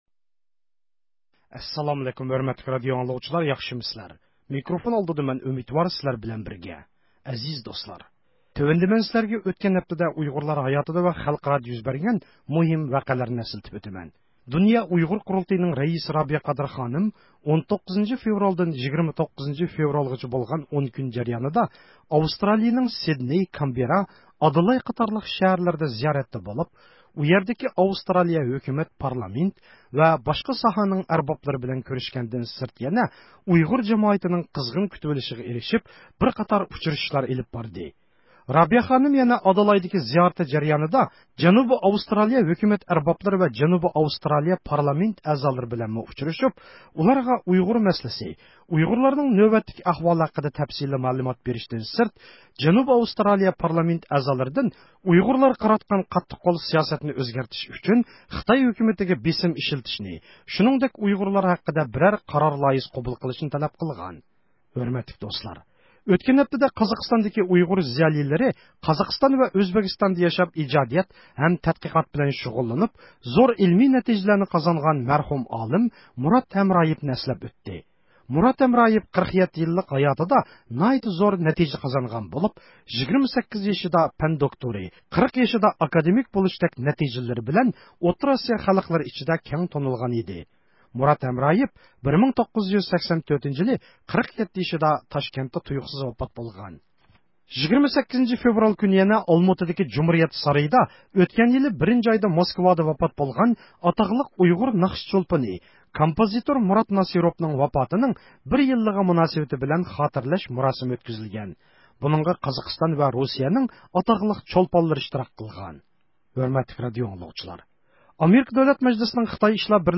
ھەپتىلىك خەۋەرلەر ( 24 – فېۋرالدىن 29 – فېۋرال) – ئۇيغۇر مىللى ھەركىتى